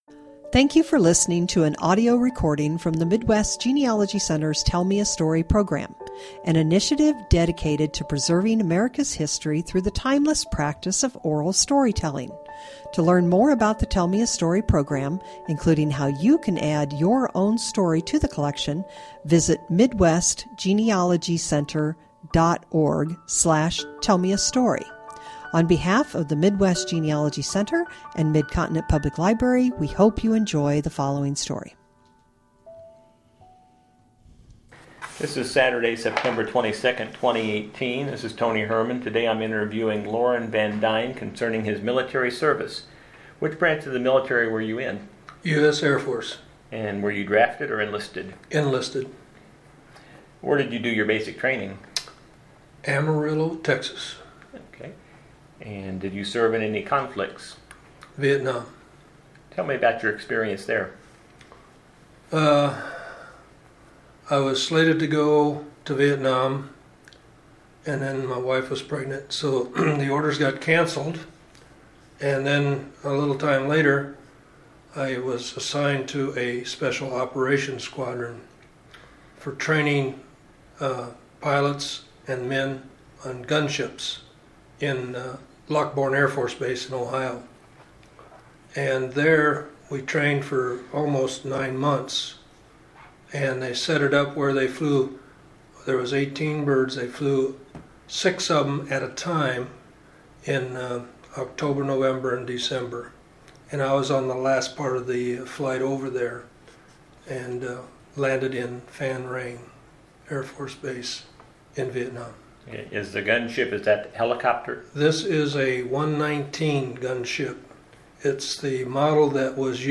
Oral Interview
Oral History